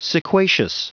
Prononciation du mot : sequacious